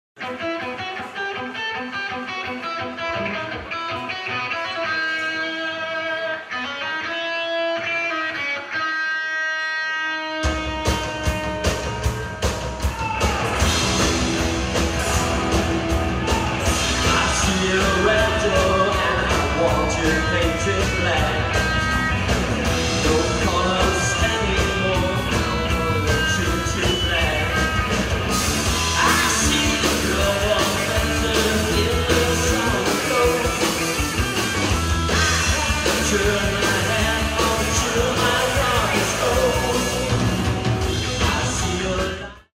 eine Gitarren betonte Live Rockband
Livemitschnitt